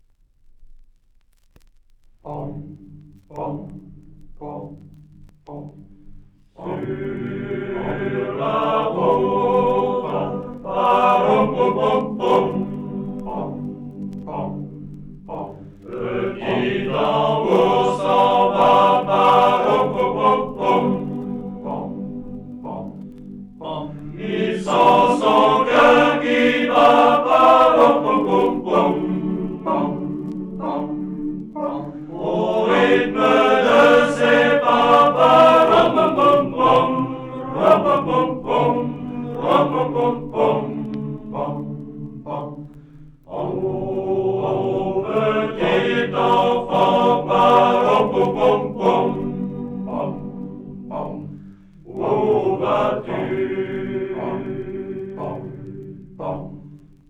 Concernant un répertoire de chant de métier vivant comme le chant militaire, il ne s’agit pas forcément des chants effectivement chantés, comme on pourra le constater.
En 1960, la Promotion LTN-COL Jeanpierre avait réalisé un enregistrement gravé sur 25 cm (matrice 10X61M16-17).
LEnfant-au-tambour-extrait-1.mp3